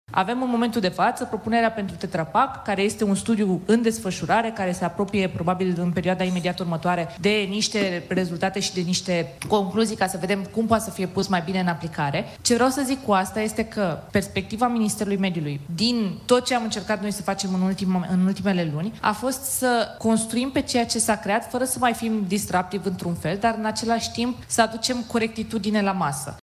Ministrul Mediului, Diana Buzoianu: Studiul pentru ambalajele de tip Tetra Pak este în desfășurare